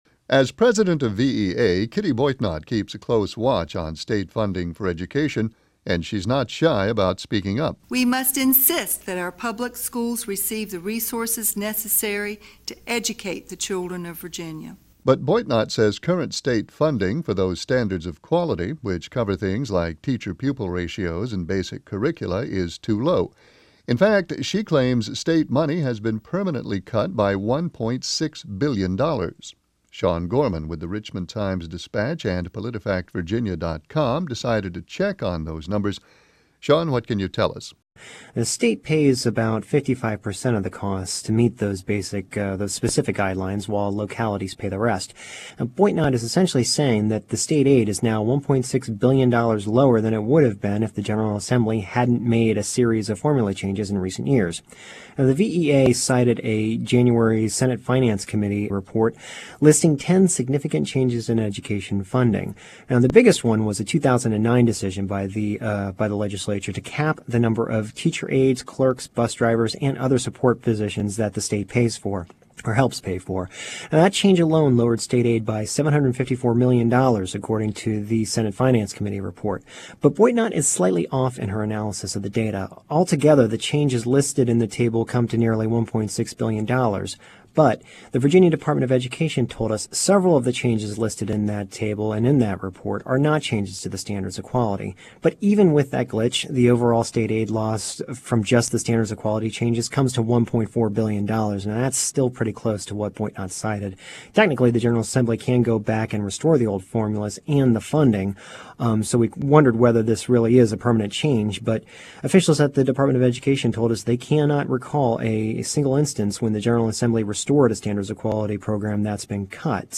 PolitiFact Virginia | Virginia Public Radio